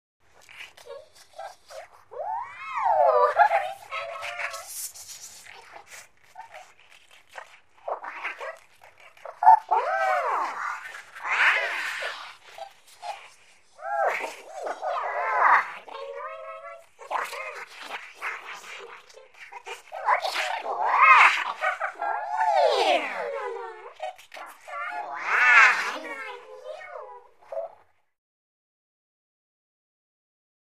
Strange Talking; Ferry-type Talking, Whispering, And Giggling.